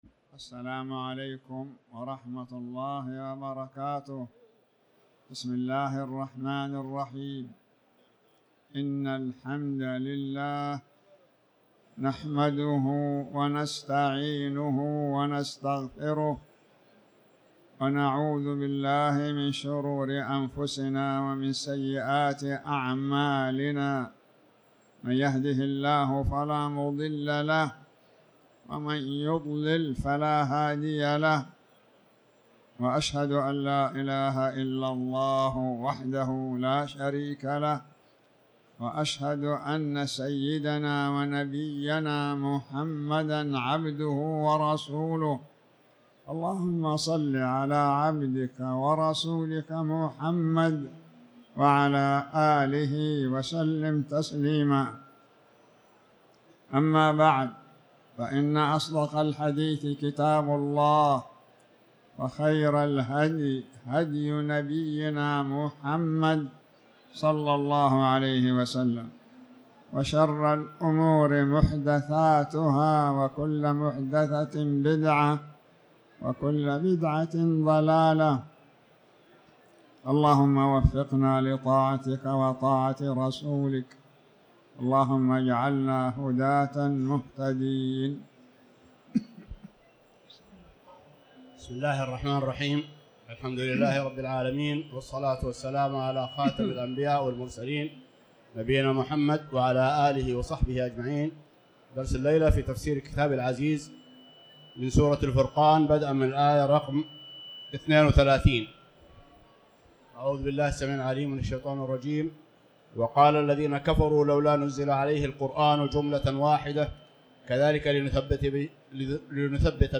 تاريخ النشر ١٨ رجب ١٤٤٠ هـ المكان: المسجد الحرام الشيخ